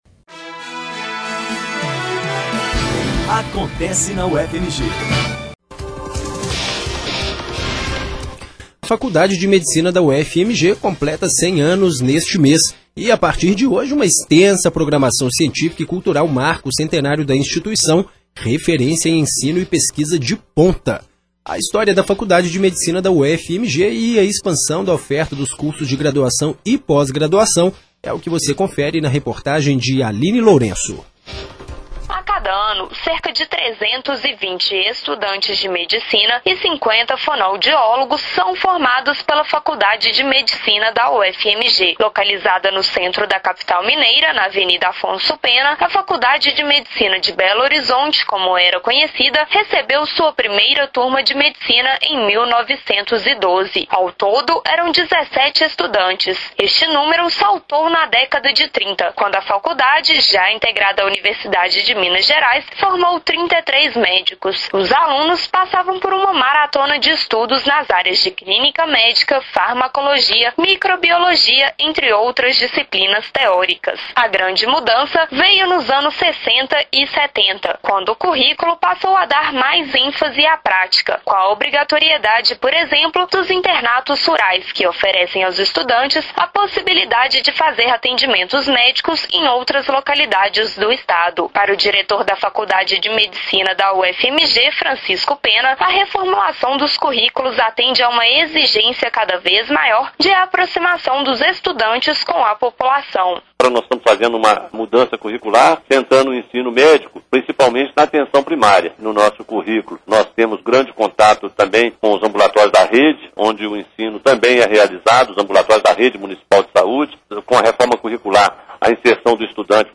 Jornalismo